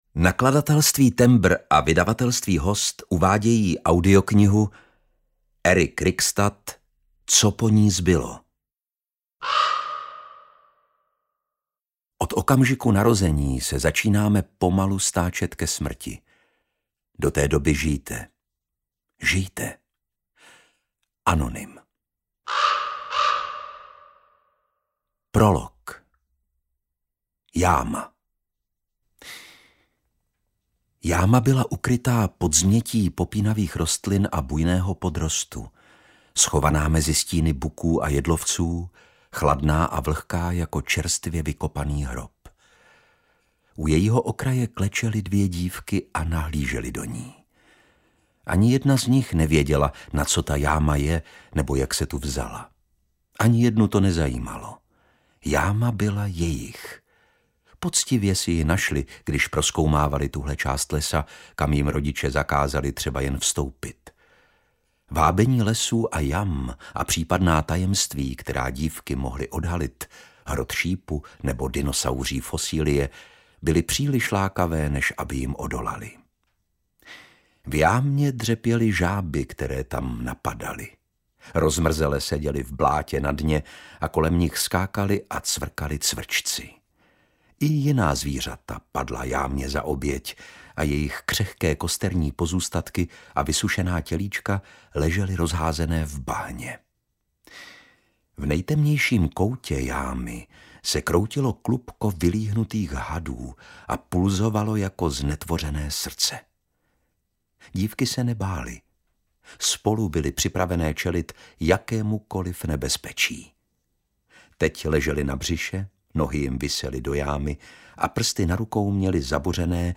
Co po ní zbylo audiokniha
Ukázka z knihy
• InterpretLukáš Hlavica